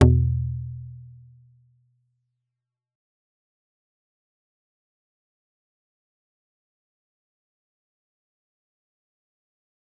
G_Kalimba-E2-f.wav